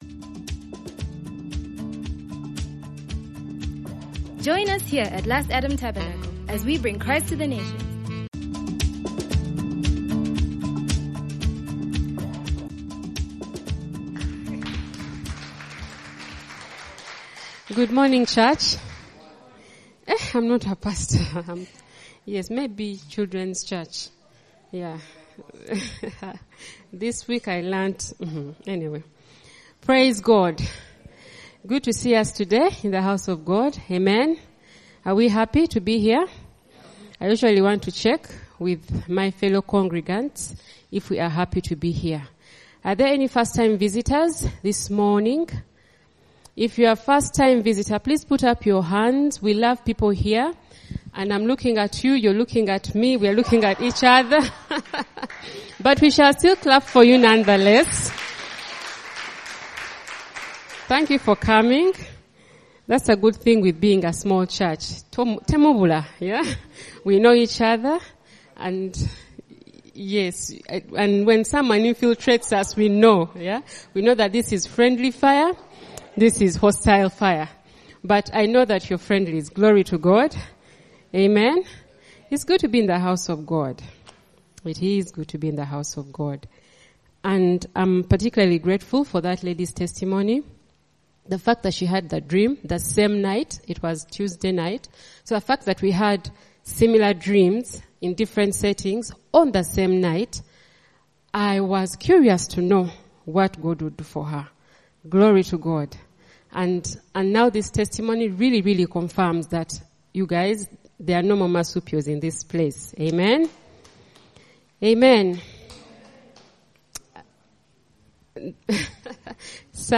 The verse also implies that there are things that could hinder believers from continually meeting together and even cause some to neglect going to church altogether. In this sermon, we are reminded of the reasons why any believer should not give up the habit of going to church.